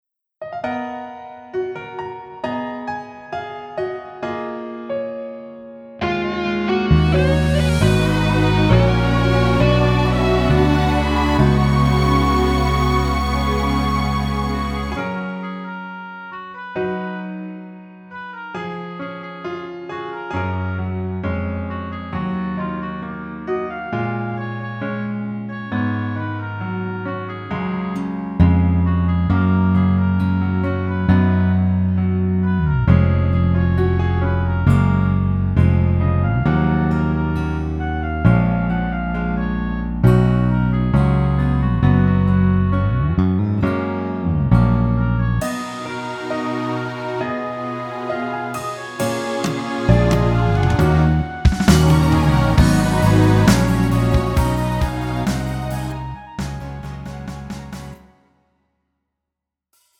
음정 원키 4:05
장르 가요 구분 Pro MR